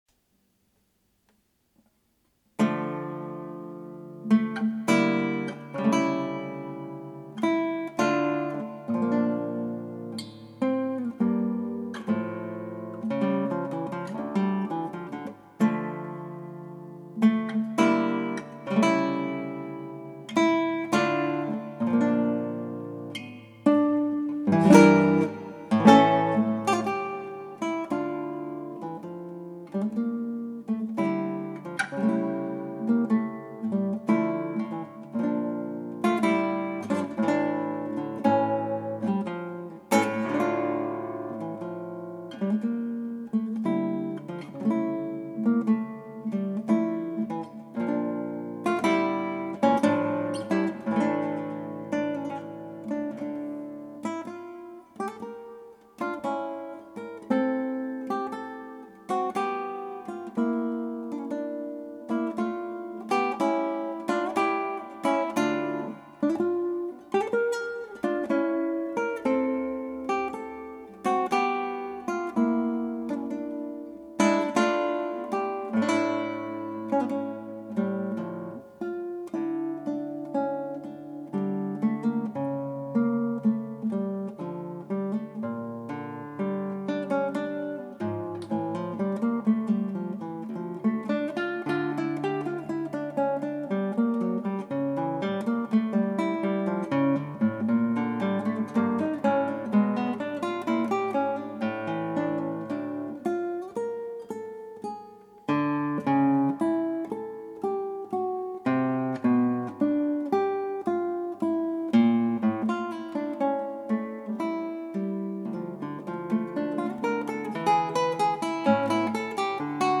クラシックギター 「こりゃしんどい」 -「ChaconneBWV1004」-
ギターの自演をストリーミングで提供